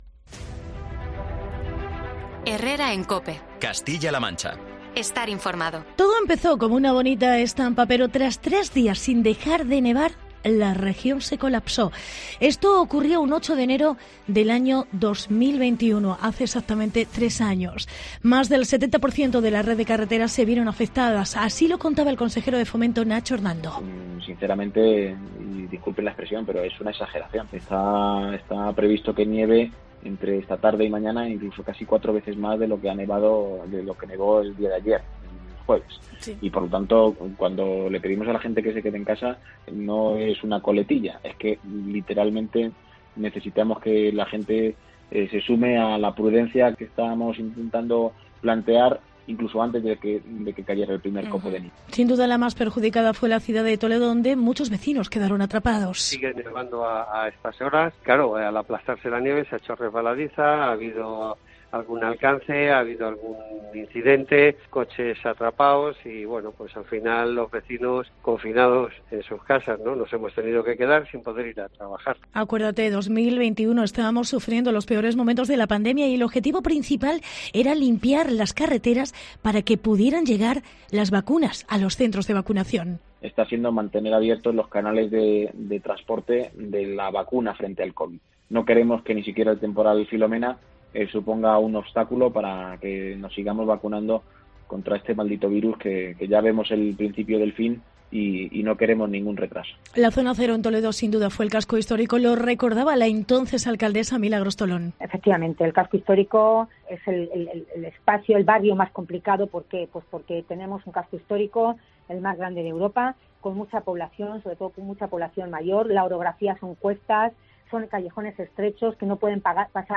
AUDIO: Escucha el reportaje en el 3 Aniversario de Filomena. Recordamos algunos sonidos y testimonios